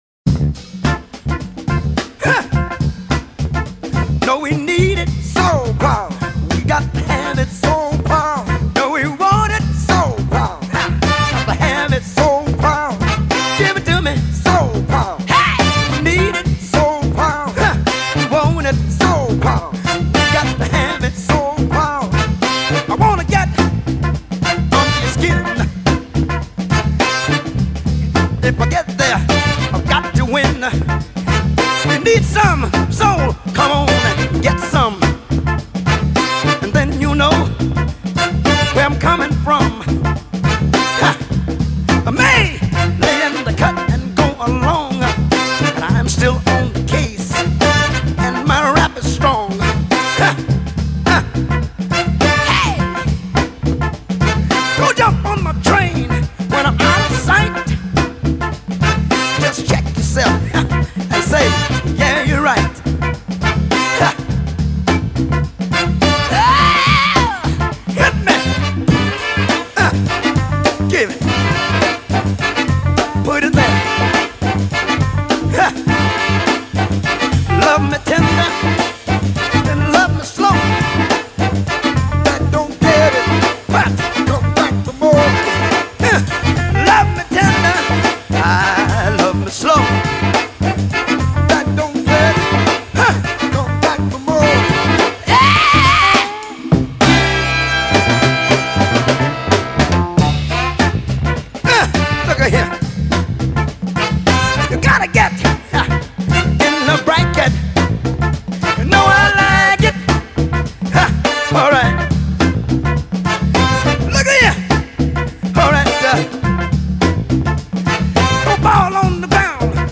call and response, verse and bridge, ad lib lyrics
bass